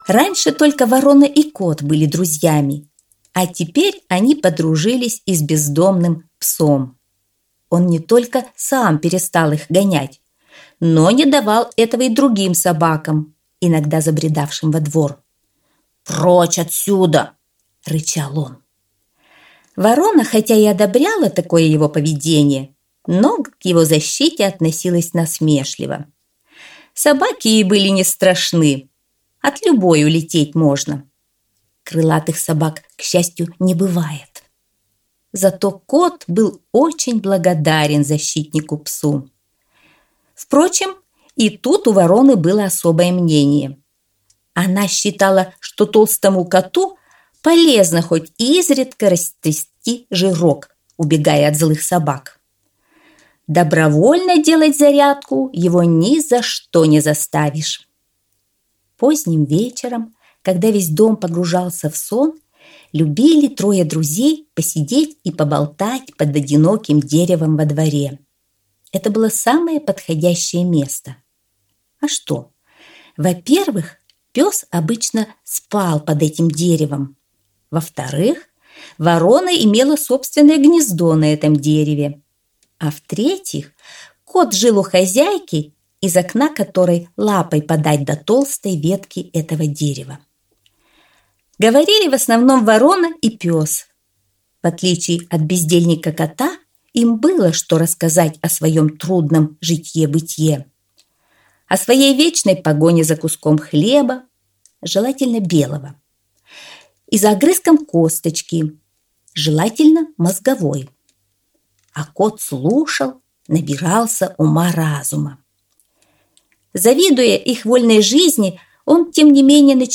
Аудиосказка «Хитрая Ворона, Пёс и Кот»